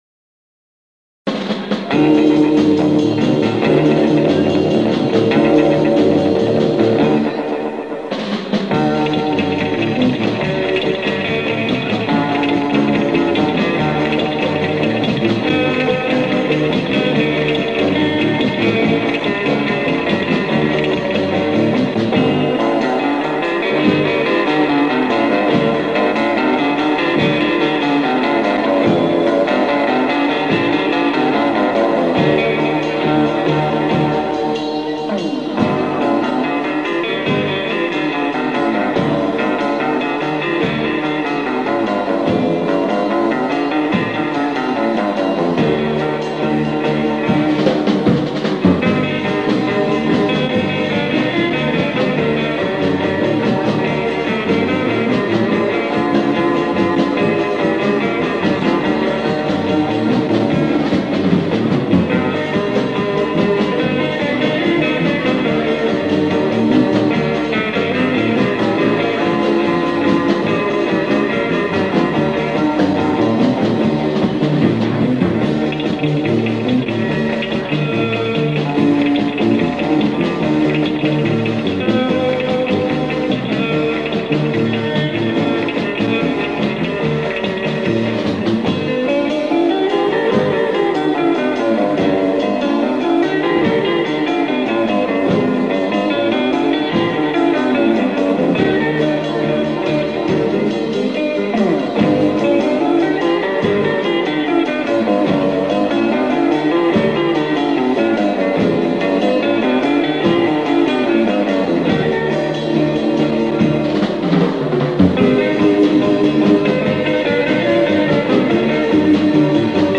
live 1960